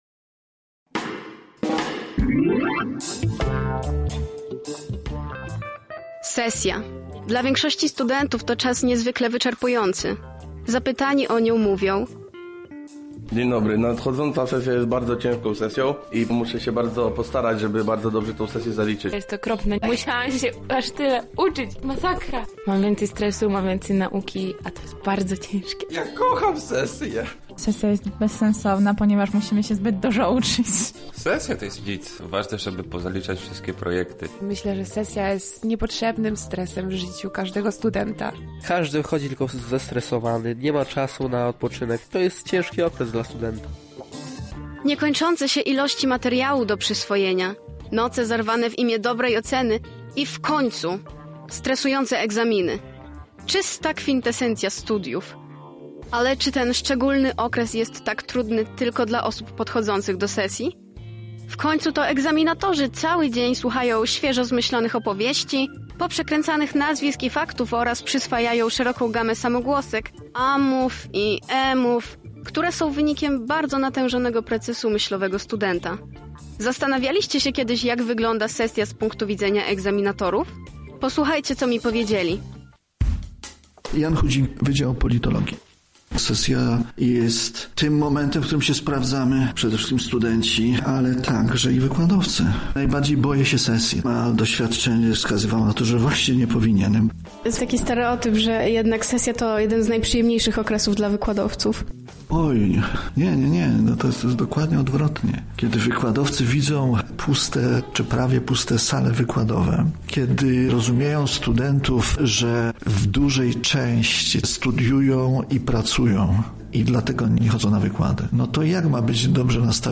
Nasza reporterka sprawdziła, co o sesji egzaminacyjnej mają do powiedzenia doktorzy i profesorowie z Uniwersytetu Marii Curie-Skłodowskiej: